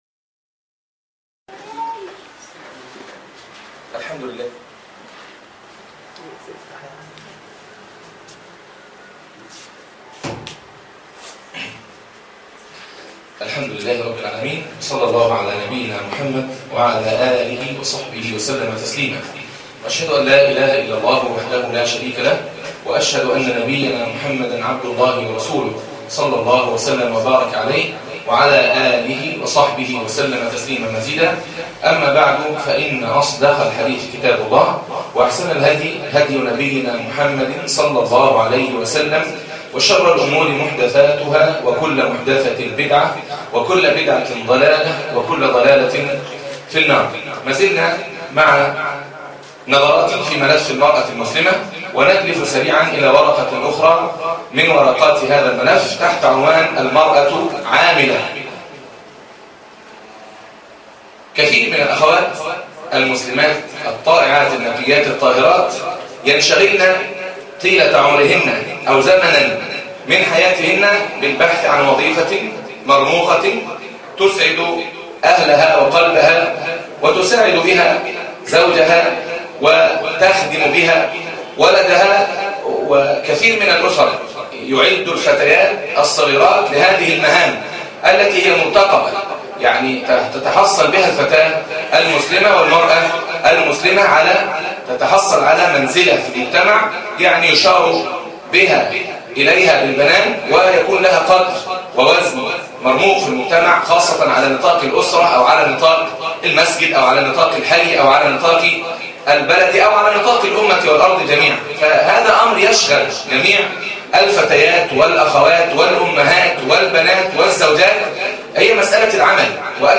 عنوان المادة الدرس الأول ( سلسلة المرأة والدعوة) تاريخ التحميل الثلاثاء 25 مايو 2010 مـ حجم المادة 13.36 ميجا بايت عدد الزيارات 1,635 زيارة عدد مرات الحفظ 471 مرة إستماع المادة حفظ المادة اضف تعليقك أرسل لصديق